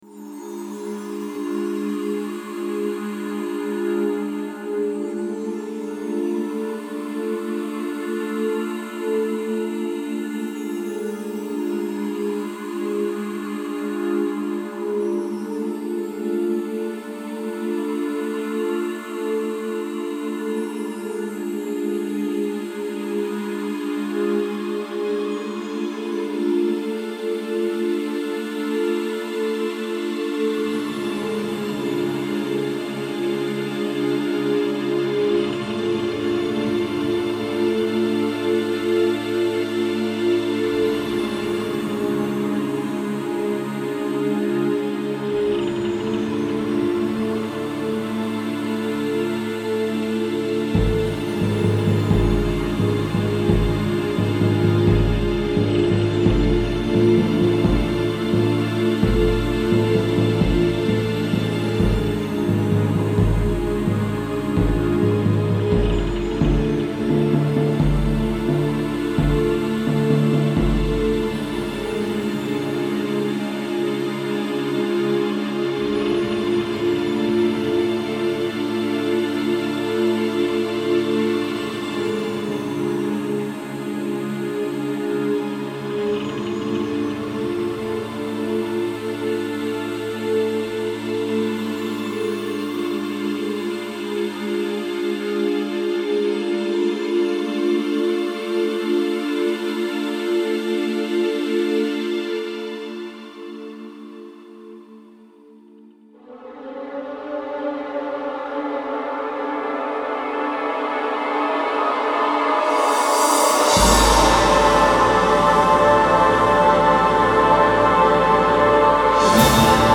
Imagine yourself in front of a large anvil in a woodsy area, perhaps near a shipwreck…